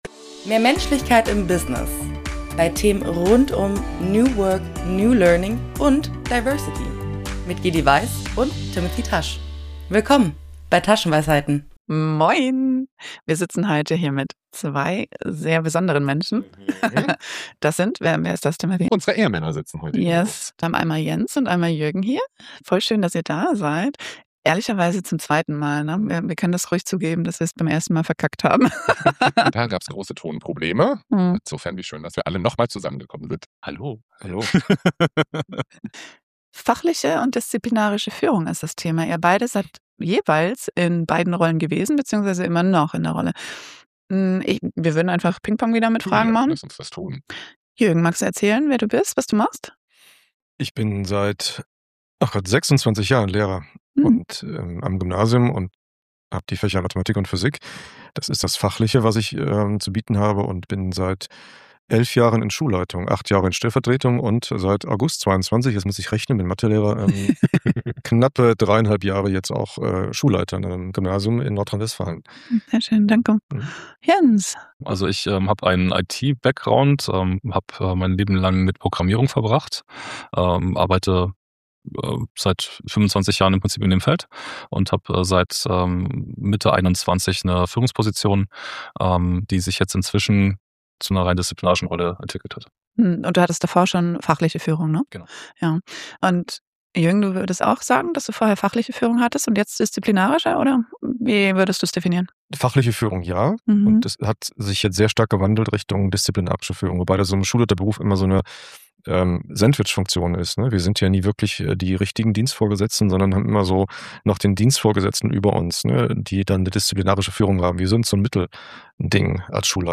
IT und Schule – zwei unterschiedliche Welten mit einer gemeinsamen Frage: Was braucht es, um Menschen sowohl fachlich als auch disziplinarisch gut und menschlich zu führen? In diesem sehr offenen Gespräch werfen die beiden einen kritischen Blick auf Strukturen sowie unterschiedliche Erwartungen und teilen ihre Herausforderungen und Tipps aus beiden Führungsrollen heraus mit uns.